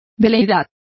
Complete with pronunciation of the translation of whim.